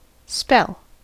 Ääntäminen
US : IPA : [ˈspɛɫ]